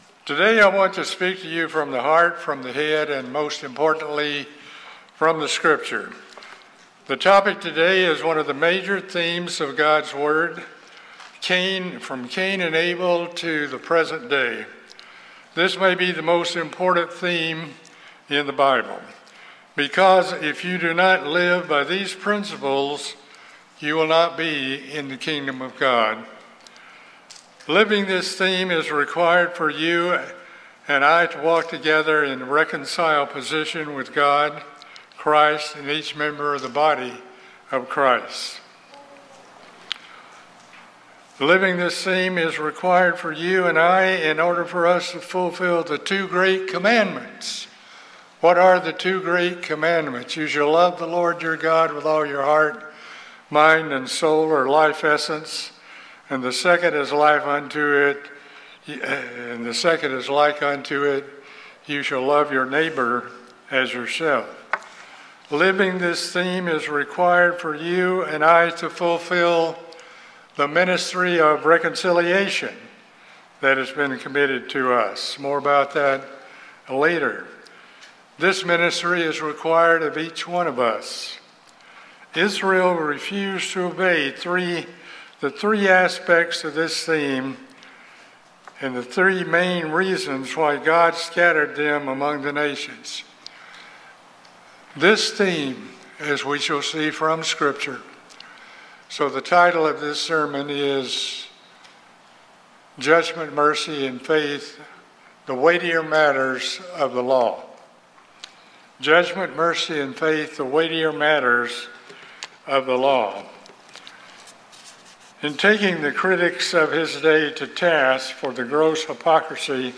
This sermon explores the fact that judgment, mercy and faith is one of the main themes of the Bible. We learn from Zechariah 7:14 that one of the main reasons Israel went into captivity and were scattered among the nations was because they did not exercise judgment, mercy and faith. Moreover, failure to exercise judgment, mercy and faith remains today one of the main problems of God’s people today.